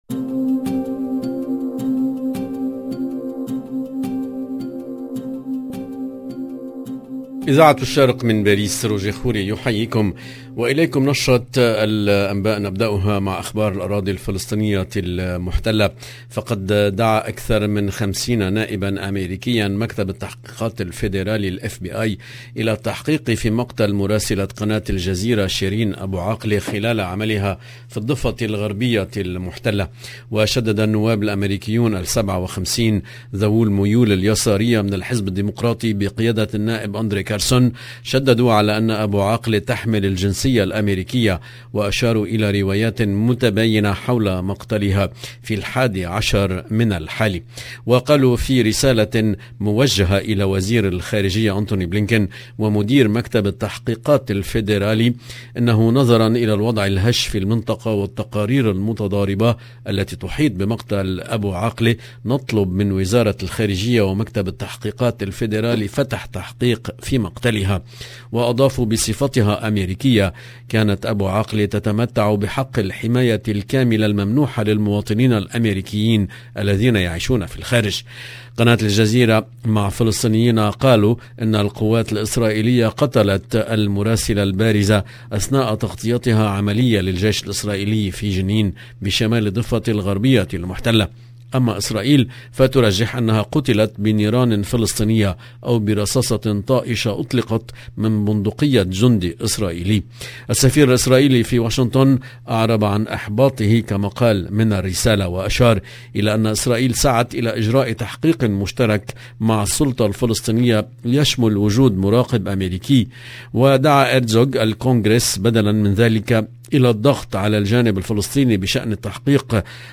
EDITION DU JOURNAL DU SOIR EN LANGUE ARABE DU 21/5/2022